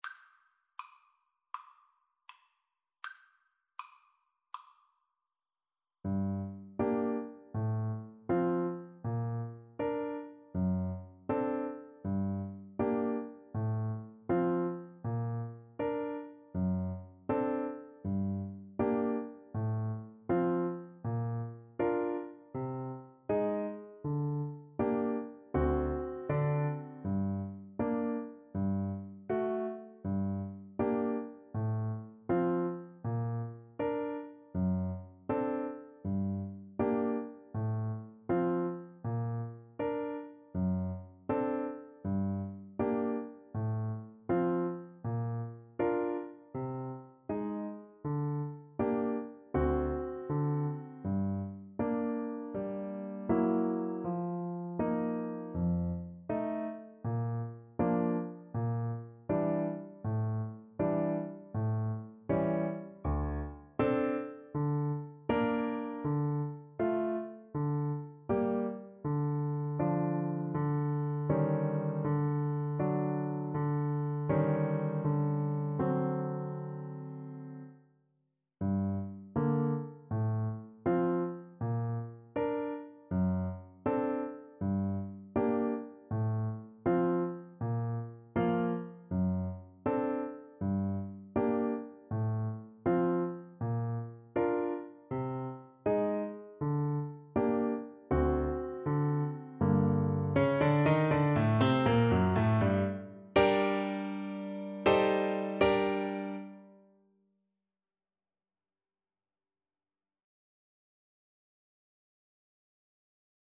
G minor (Sounding Pitch) (View more G minor Music for Oboe )
Andante = c.80
Classical (View more Classical Oboe Music)